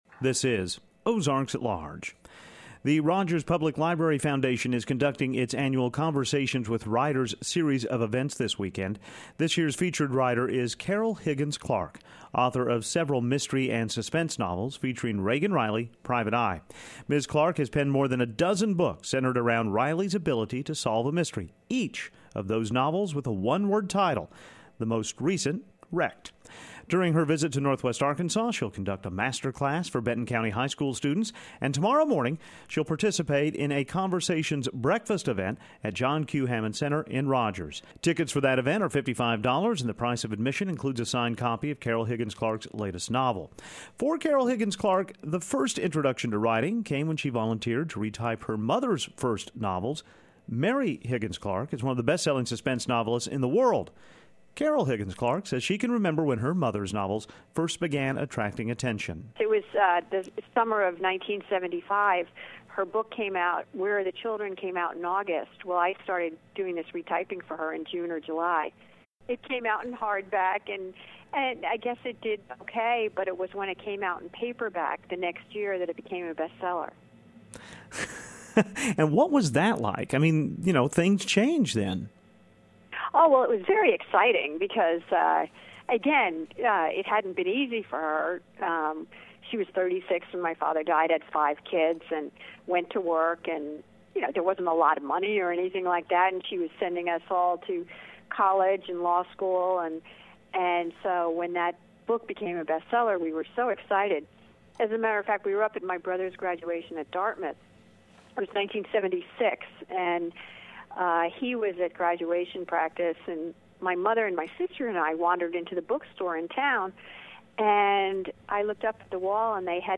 Mysteries and the Keys to Writing Carol Higgins Clark.mp3 Carol Higgins Clark is the keynote author for this year's Conversation Series sponsored by the Rogers Public Library Foundation. In a conversation this week she talked about many topics, including how she became a novelist.